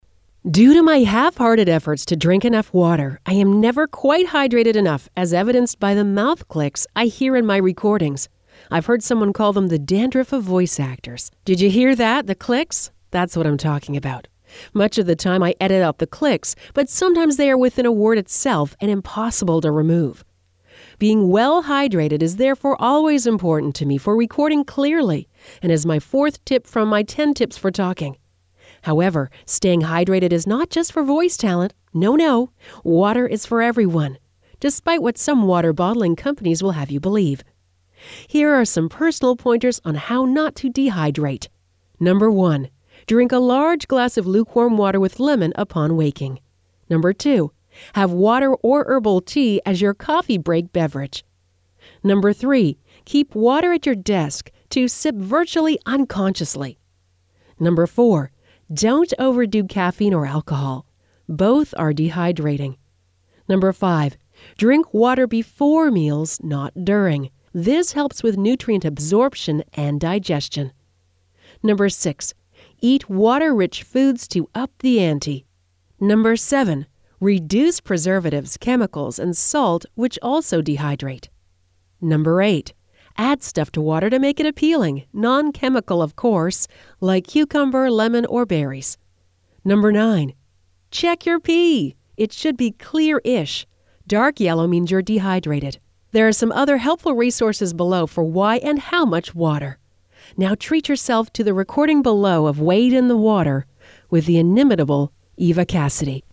Much of the time, I edit out the clicks, but sometimes they are within a word itself and impossible to remove.